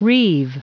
Prononciation du mot reave en anglais (fichier audio)
Prononciation du mot : reave